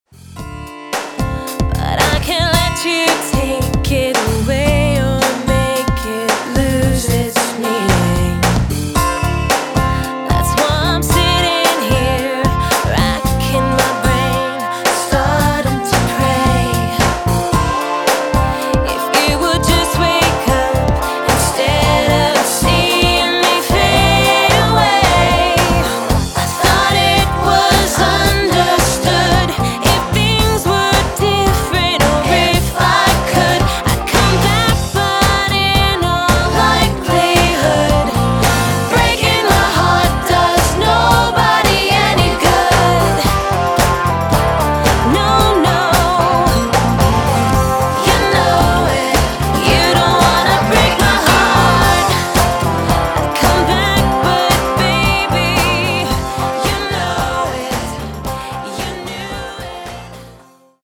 pop artist
With strong rhythms and